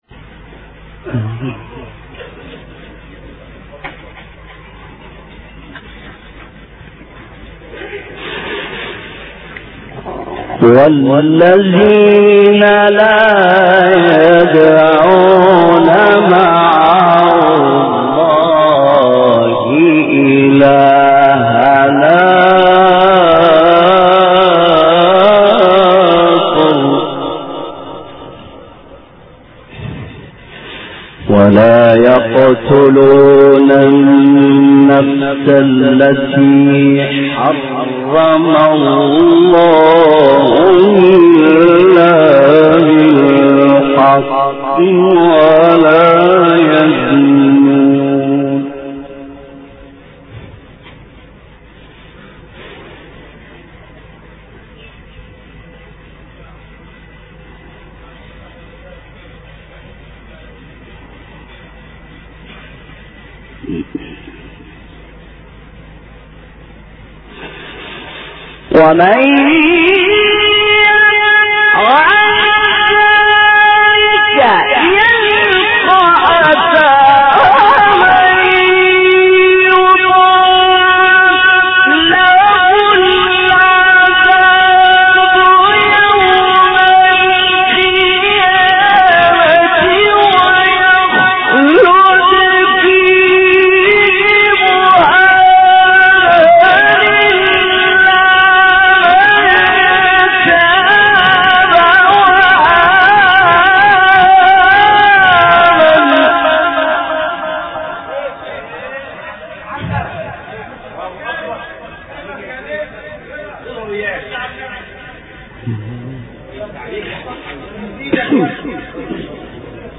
قران مجود